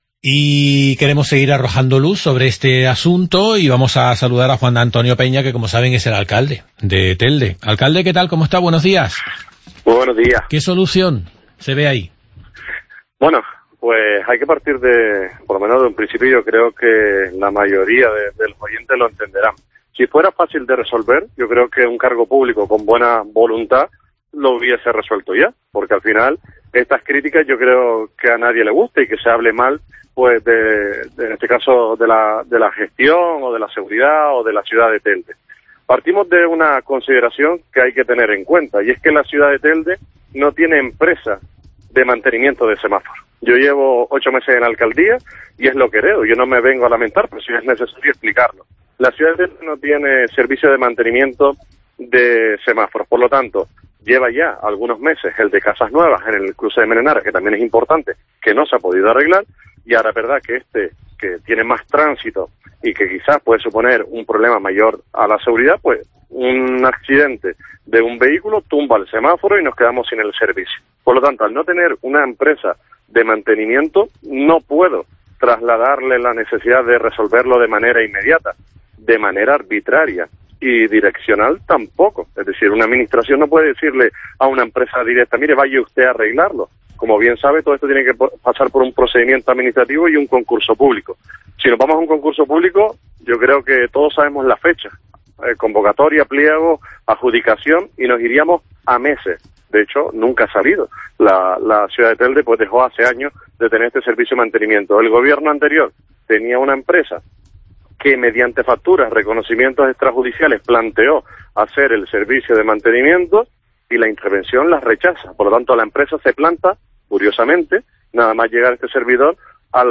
Juan Antonio Peña, alcalde de Telde